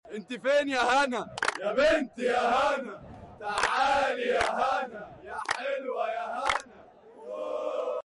🔥 مظاهرة كلها طاقة وحب: Sound Effects Free Download
أجواء مجنونة ومليانة طاقة